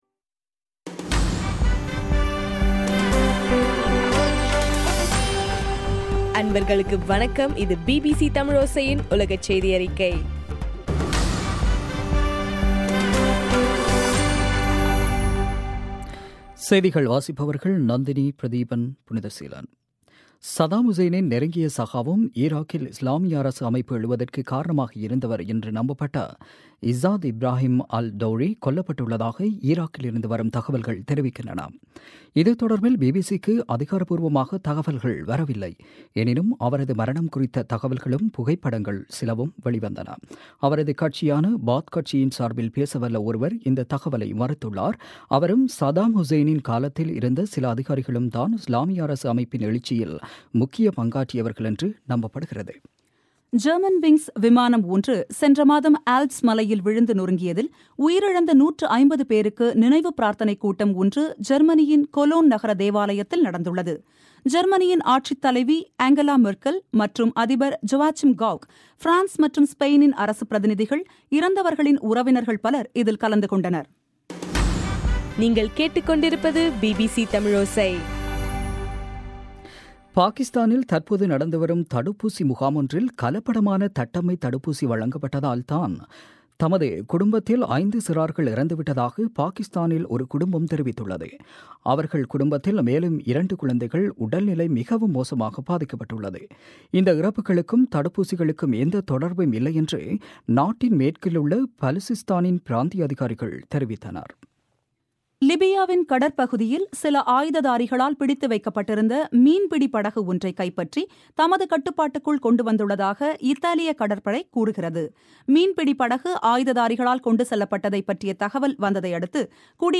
தமிழோசையின் உலகச் செய்தியறிக்கை